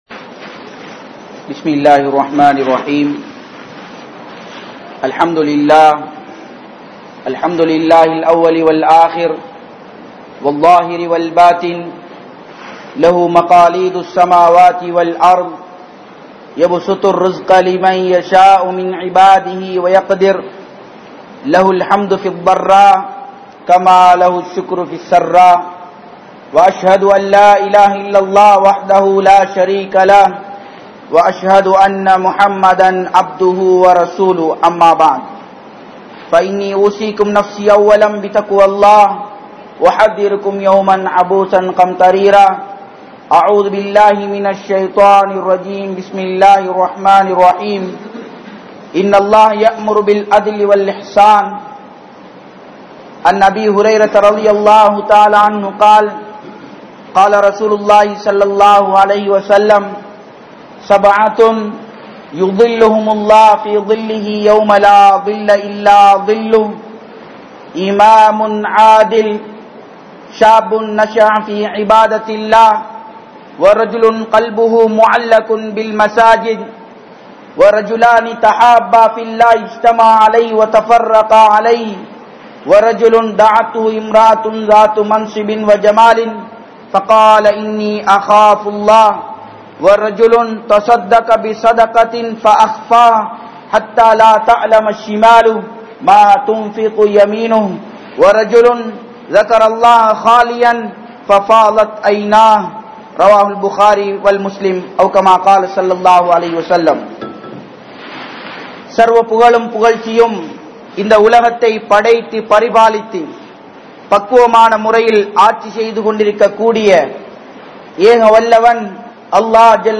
Neethamaaha Nadavugal (நீதமாக நடவுங்கள்) | Audio Bayans | All Ceylon Muslim Youth Community | Addalaichenai